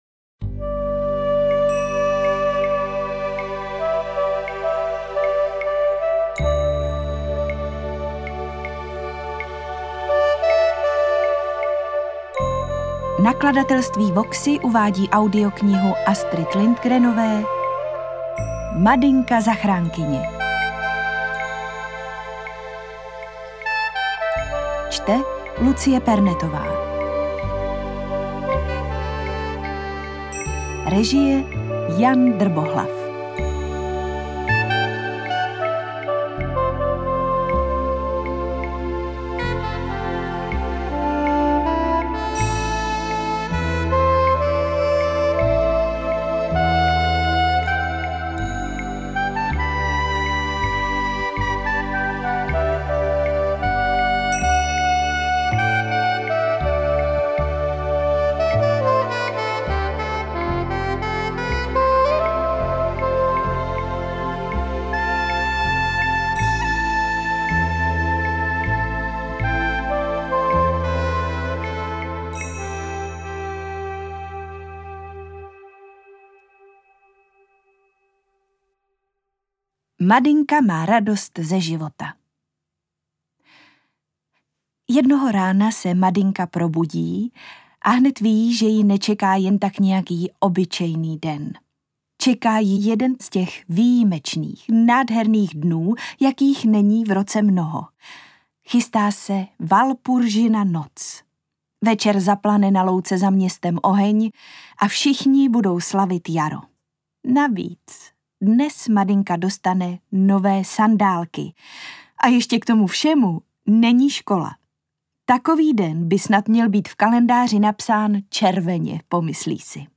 AudioKniha ke stažení, 10 x mp3, délka 6 hod., velikost 493,7 MB, česky